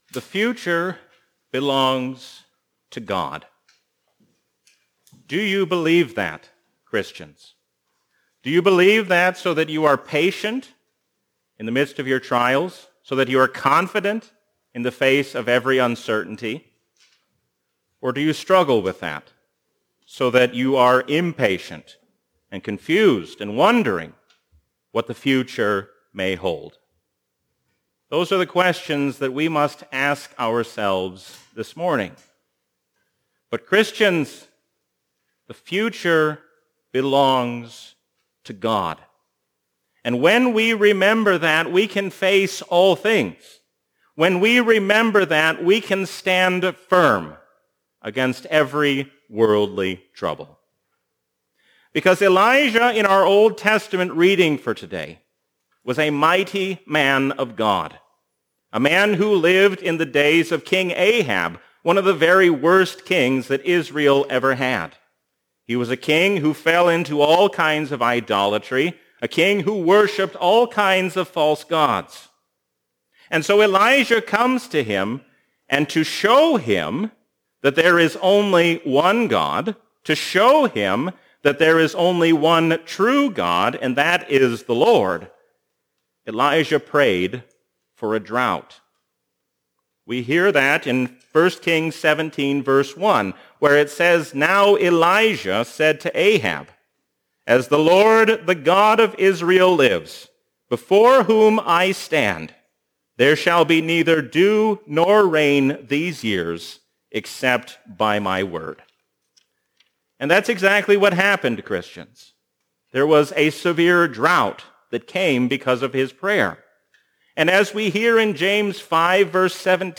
A sermon from the season "Trinity 2021."